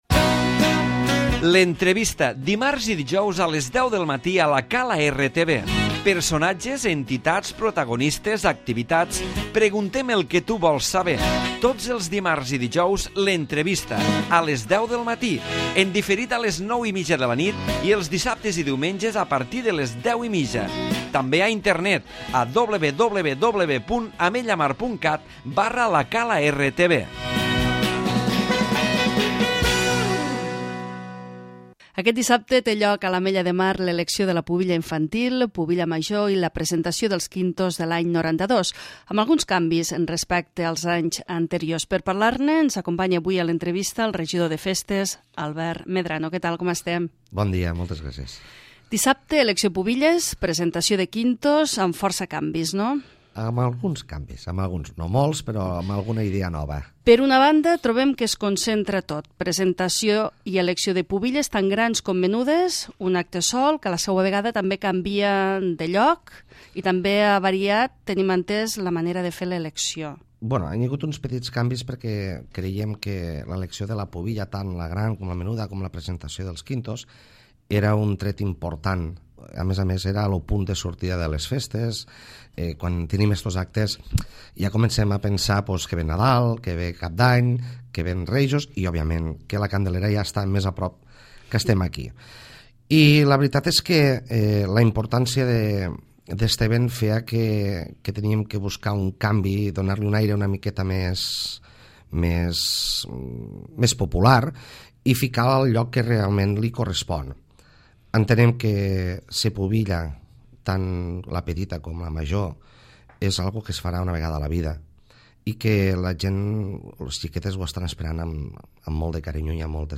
L'Entrevista
Avui a la nostra entrevista amb el regidor de festes A. Medrano hem conegut més detalls pel que fa l'elecció de les pubilles 2012 i la presentació de quintos que tindrà lloc esta dissabte 26 de novembre a les 7h de la tarda al poliesportiu.